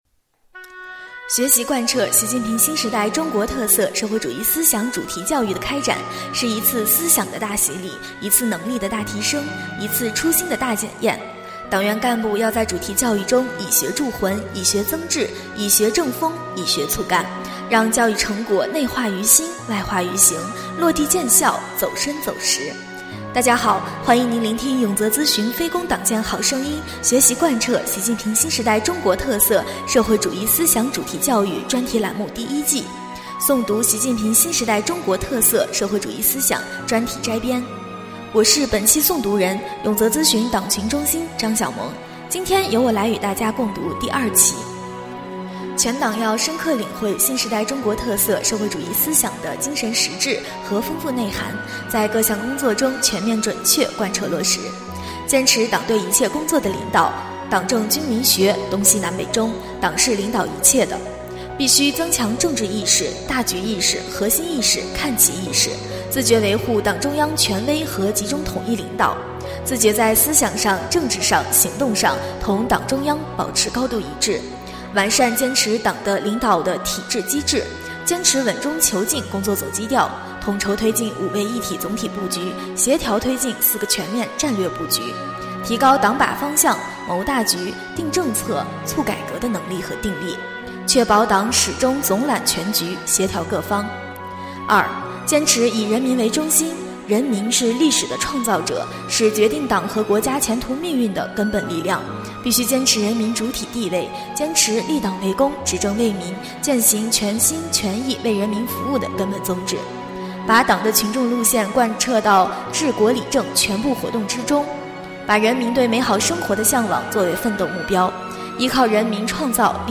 【诵读】《习近平新时代中国特色社会主义思想专题摘编》第2期-永泽党建